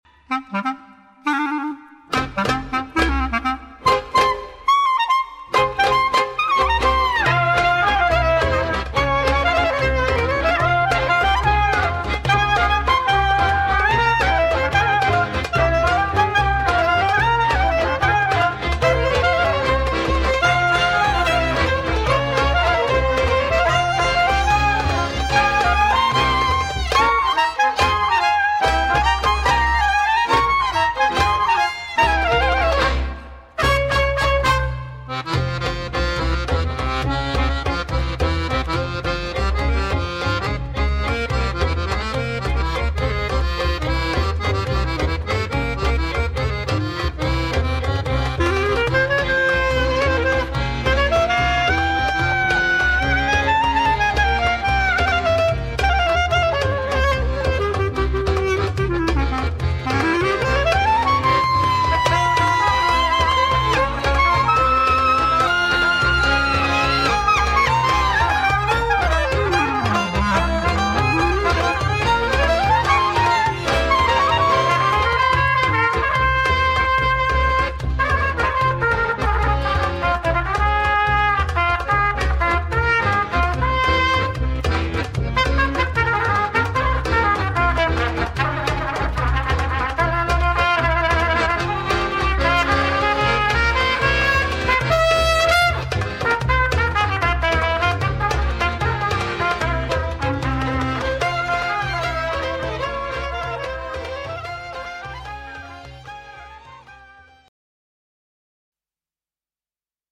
Clarinete, canto
acordeón
violín
laúd árabe, trompeta
percusiones orientales
contrabajo